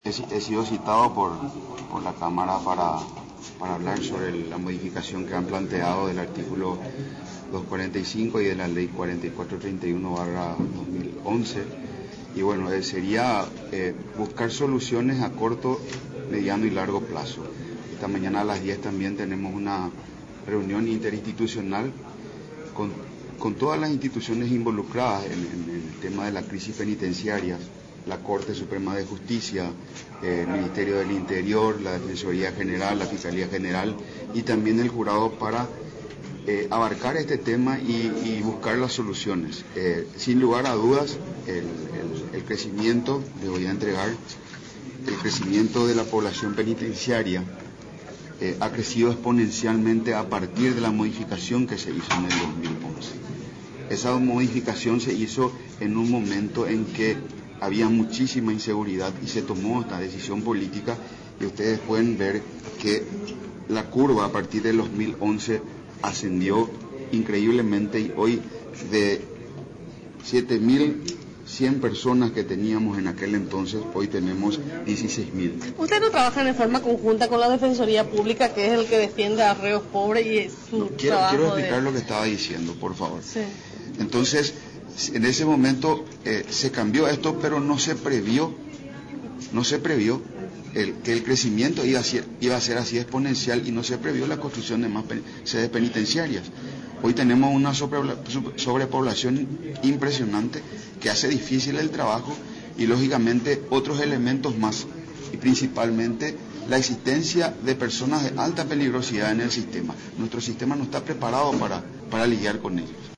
El ministro Julio Javier Ríos, expuso este lunes sobre este proyecto de modificación en la Comisión de la Reforma Penitenciaria en el Senado.